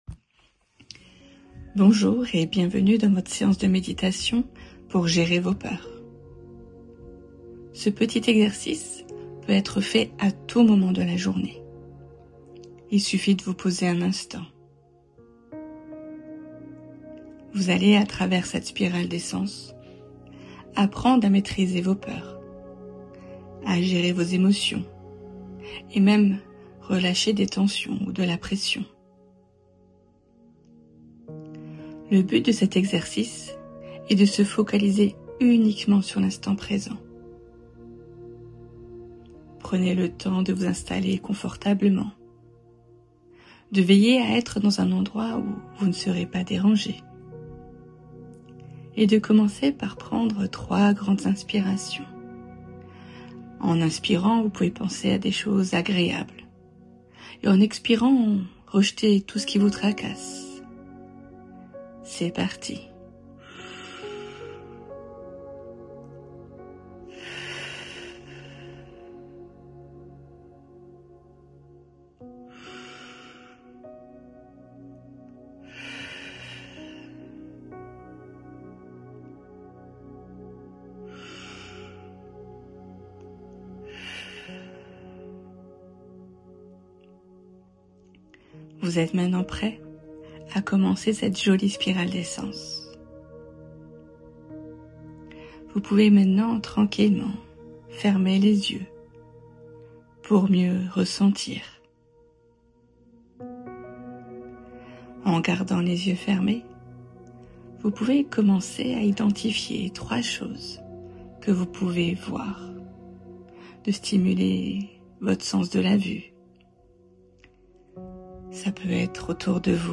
Méditation - J'apprivoise la peur